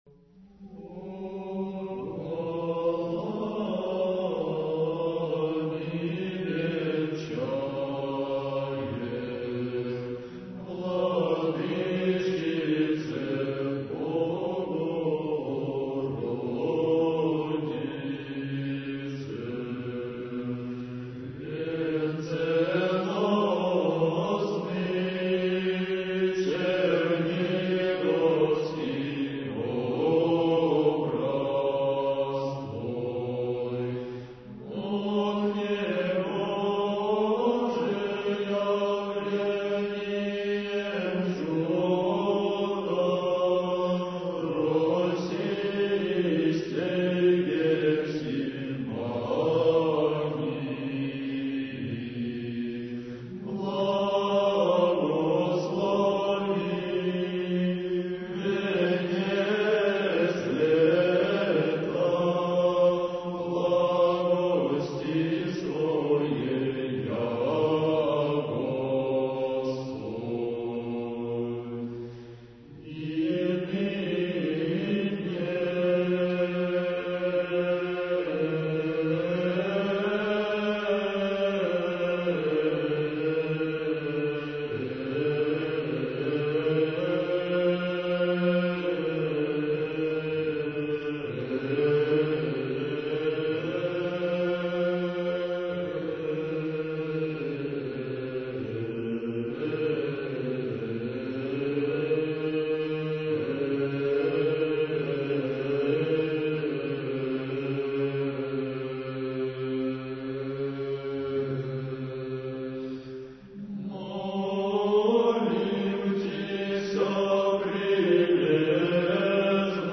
Духовная музыка / Русская